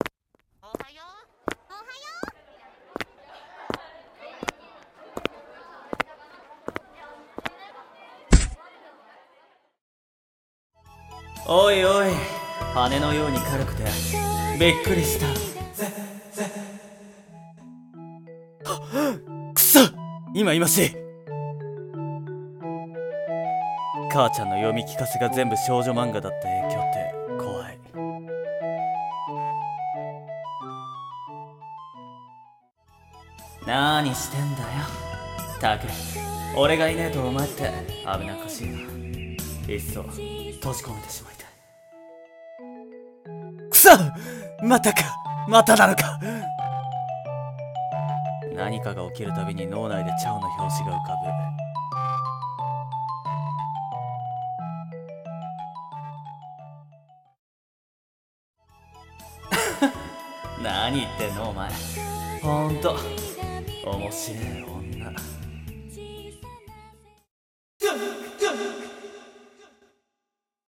さんの投稿した曲一覧 を表示 ラブコメ体質 【掛け合い 二人声劇】